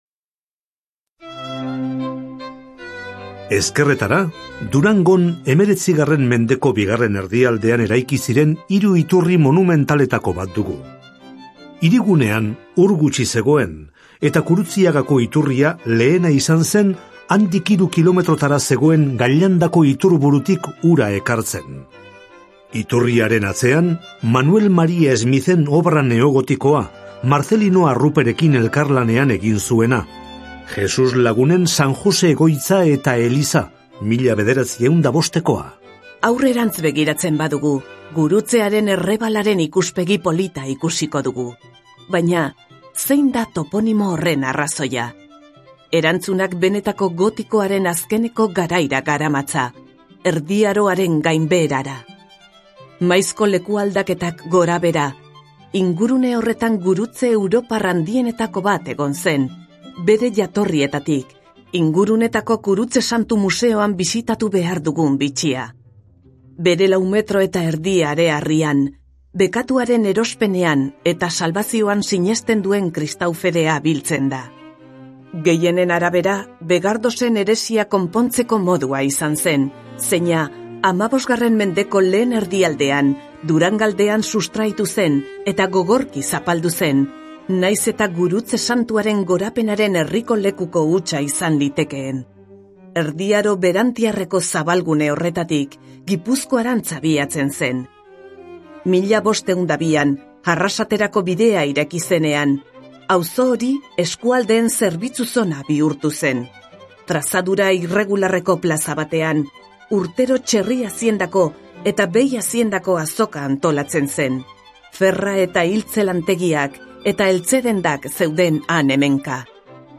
BISITA AUDIOGIDATUAK DURANGON - VISITAS AUDIOGUIADAS EN DURANGO